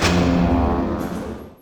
Some powerup/startup sound experiments
44khz IMA 4bit mono.